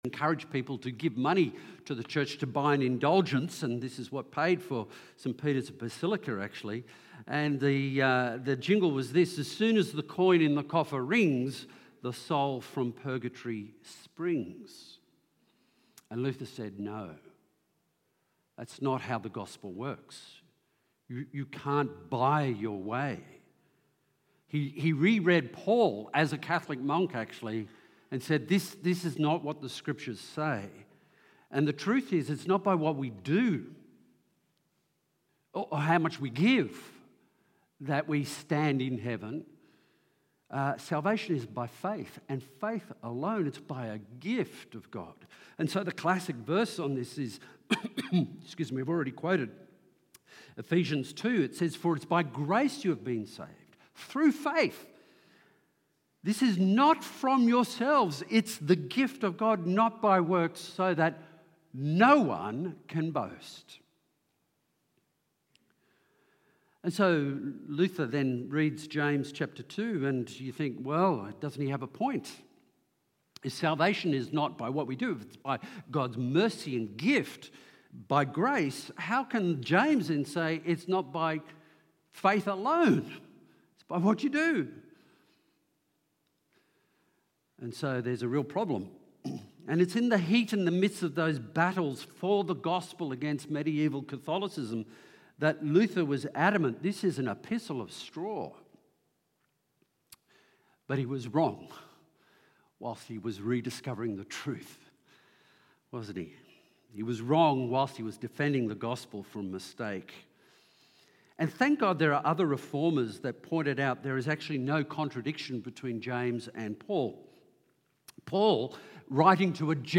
This sermon explores the profound connection between faith and deeds, drawing from the teachings of Paul and James to clarify their seemingly contrasting views on salvation.